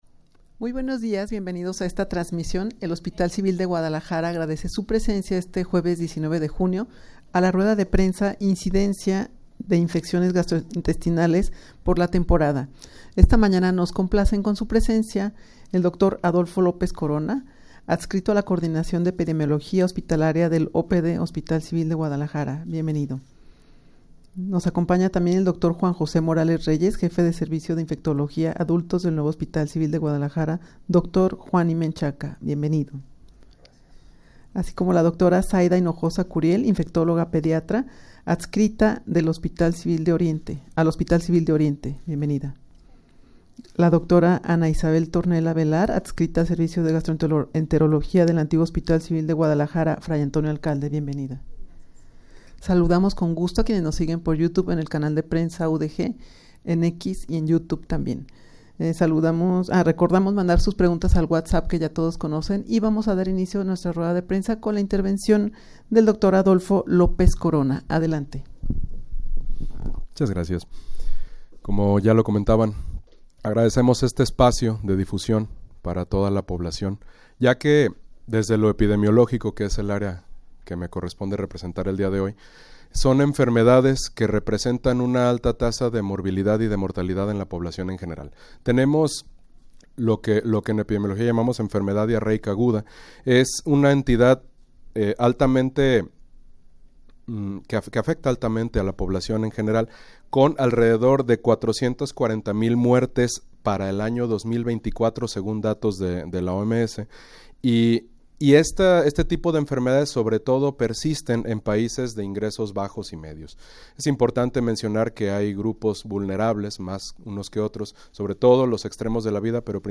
Audio de la Rueda de Prensa
rueda-de-prensa-incidencia-de-infecciones-gastrointestinales-por-la-temporada.mp3